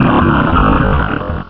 Cri de Regirock dans Pokémon Rubis et Saphir.